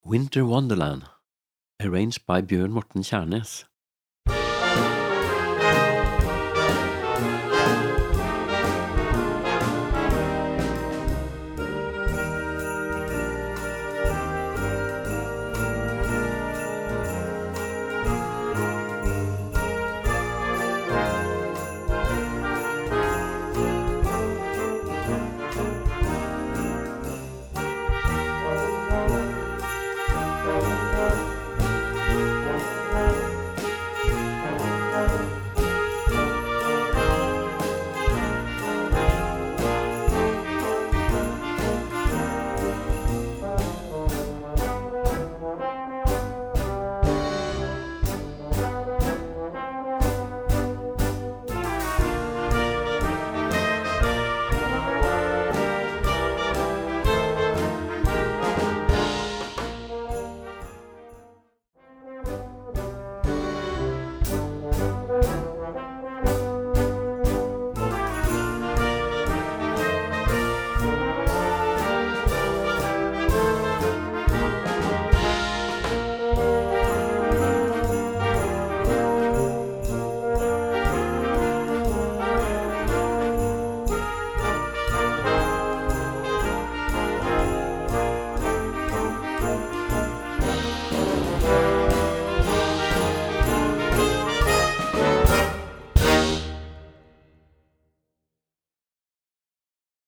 Weihnachtsmusik für Jugendblasorchester
Besetzung: Blasorchester